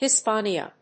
/hɪspéɪniə(米国英語)/